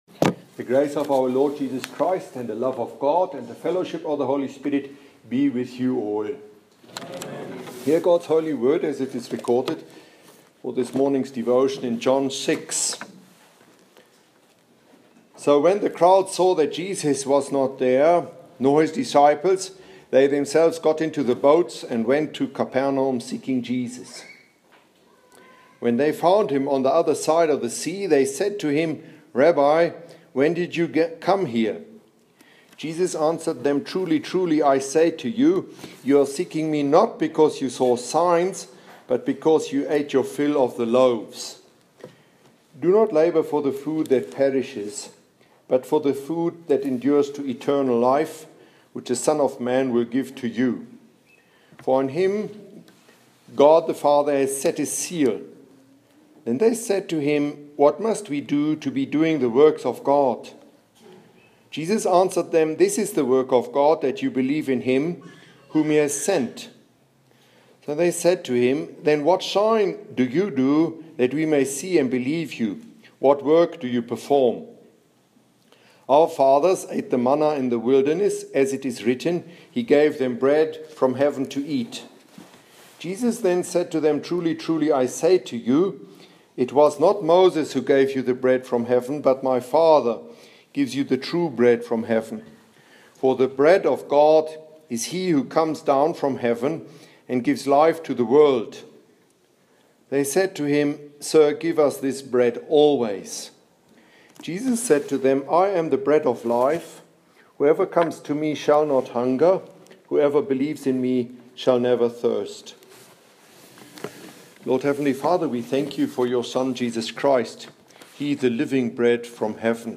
Here is this morning's sermon during Monday's Matins in the chapel of St.Timothy at the Lutheran Theological Seminary in Tshwane (Pretoria, South Africa) to listen too: